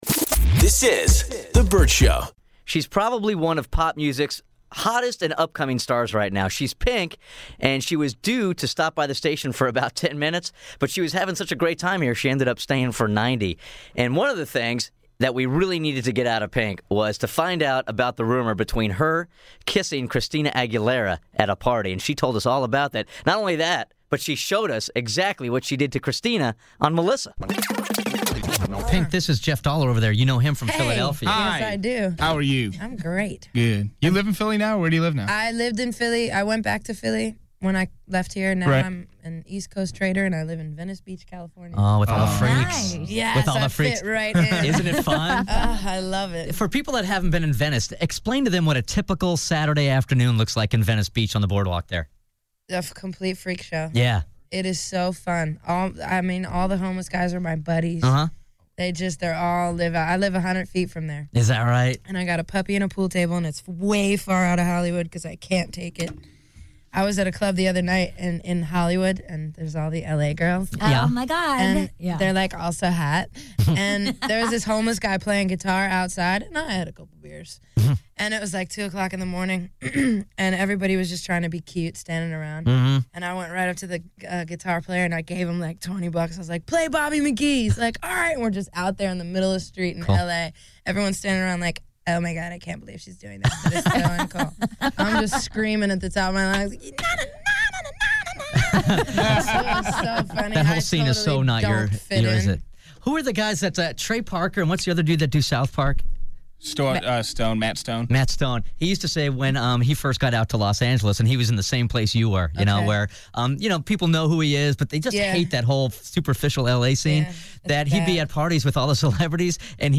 Vault: Interview Pink